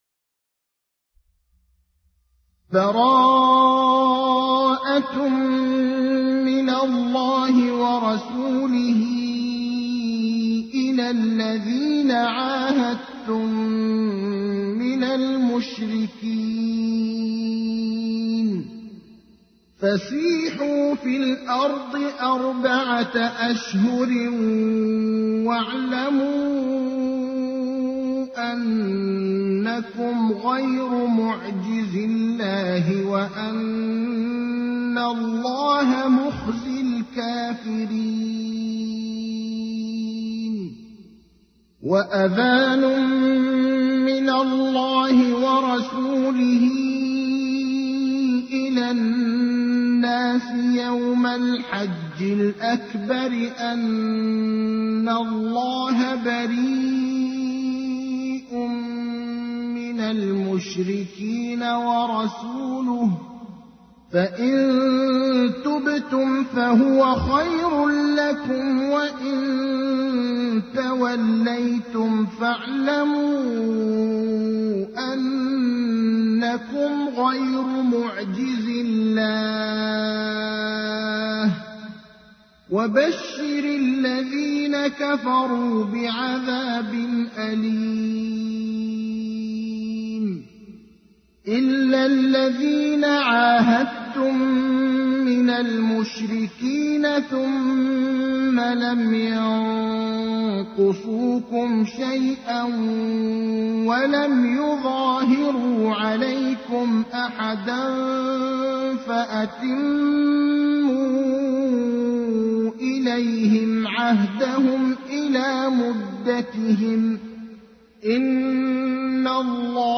تحميل : 9. سورة التوبة / القارئ ابراهيم الأخضر / القرآن الكريم / موقع يا حسين